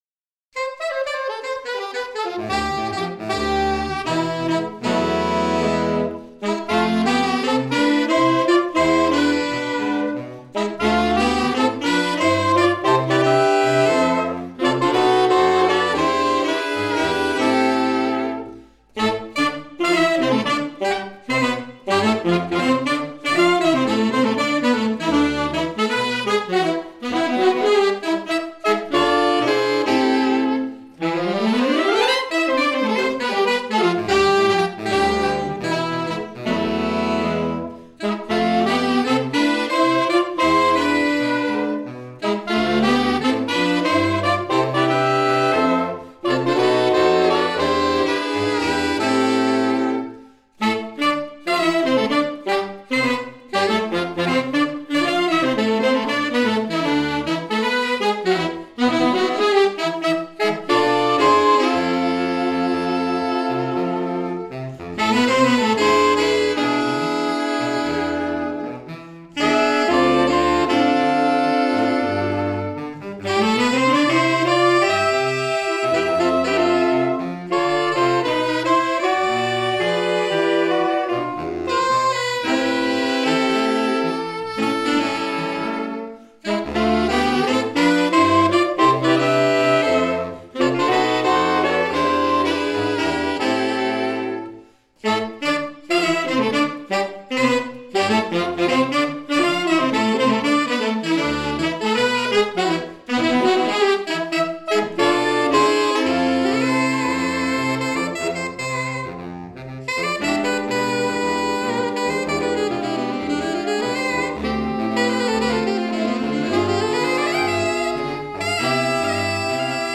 5 Damen - 5 Saxophone!